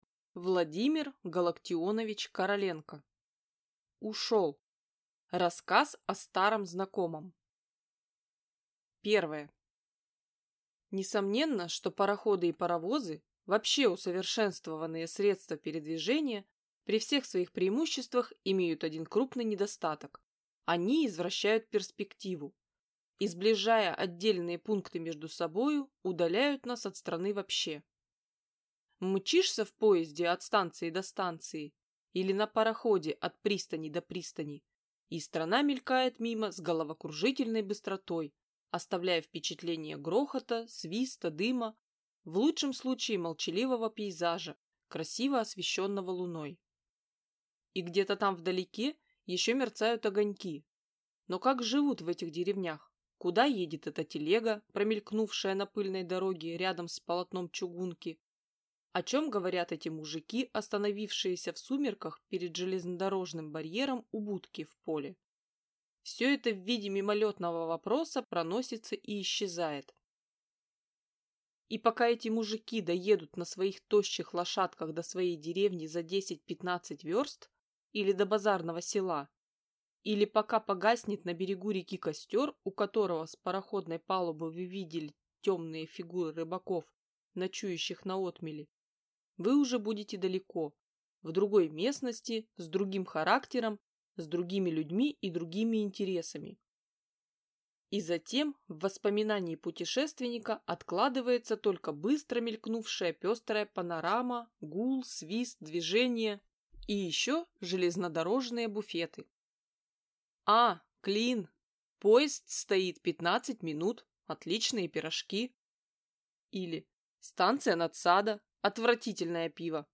Аудиокнига Ушел!
Прослушать и бесплатно скачать фрагмент аудиокниги